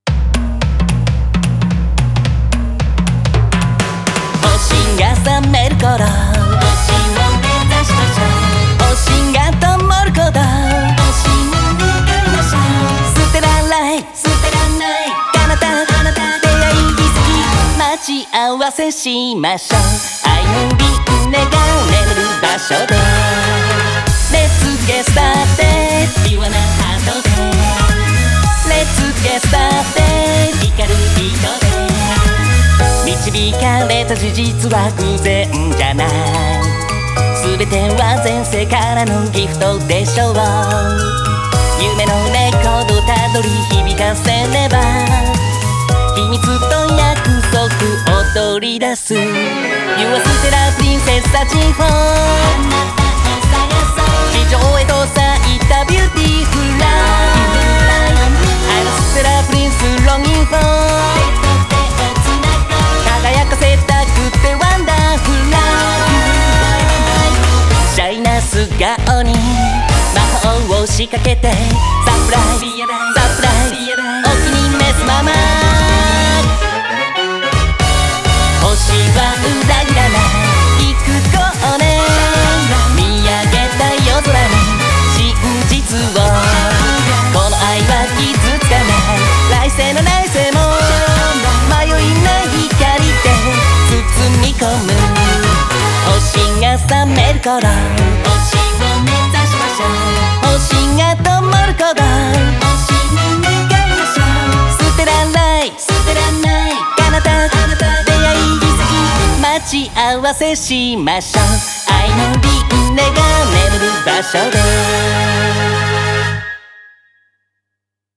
BPM110
Audio QualityPerfect (High Quality)
The true terror is the swing rhythm